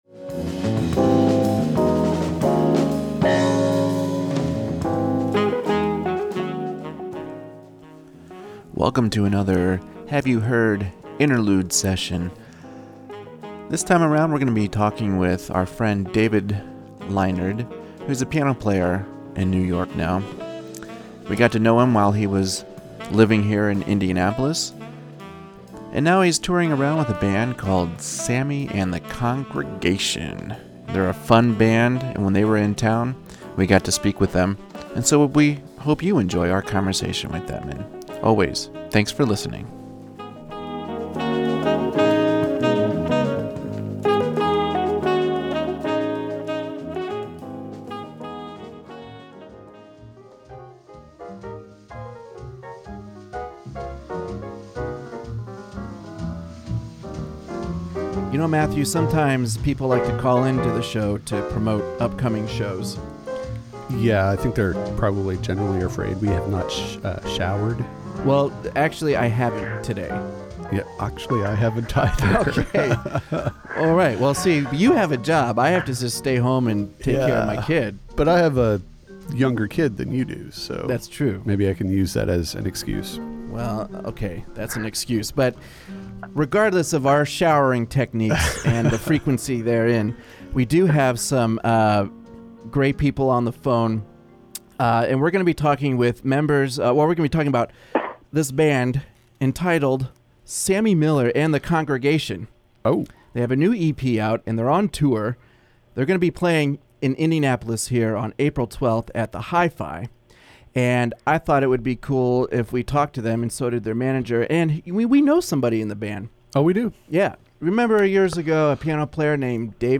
As you can tell while listening to our conversation and their music, these guys have fun no matter what they’re doing!
This was a fun interview and I hope you have as much fun listening to it as we did recording it!